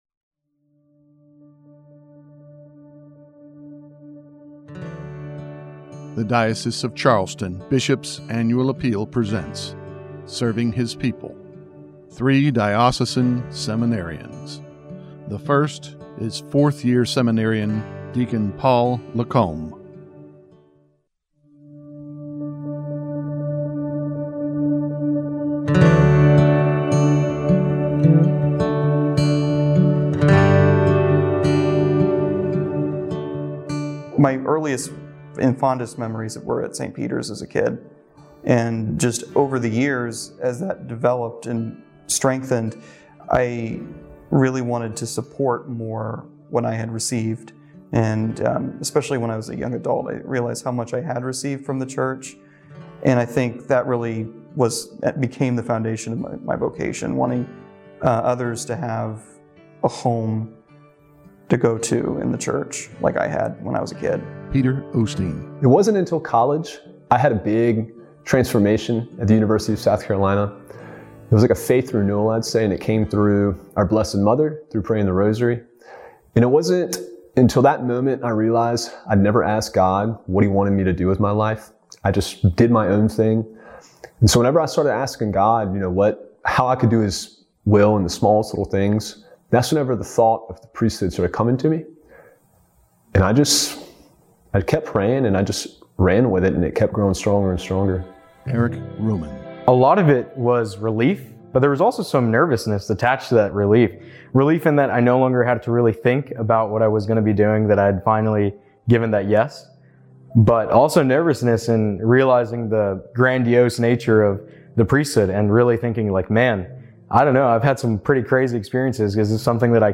Three diocesan seminarians